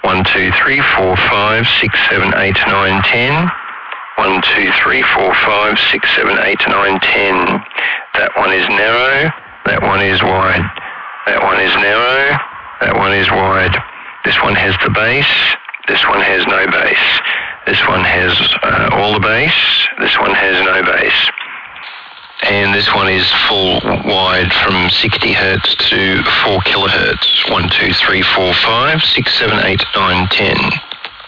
Very weak Bass/Nobass test @ -10 dB SNR This test compares a highpass cutoff of 60 Hz and 250 Hz at a very poor S/N ratio of -10dB.